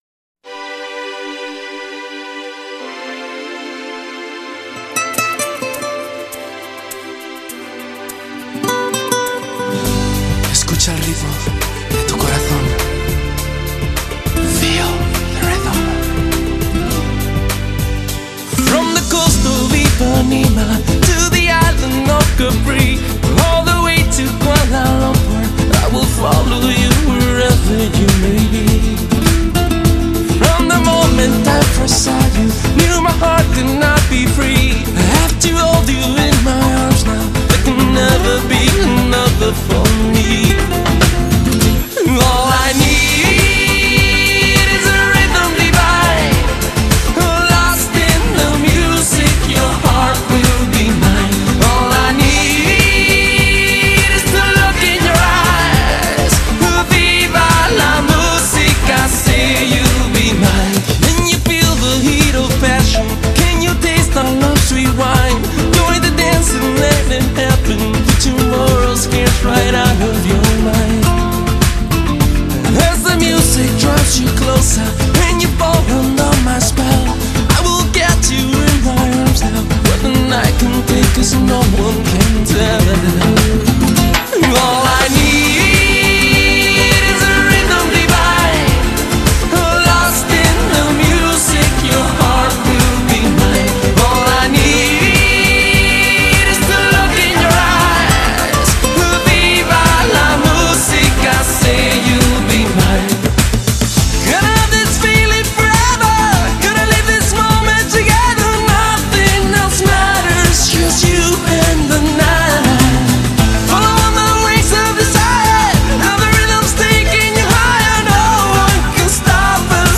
08 Samba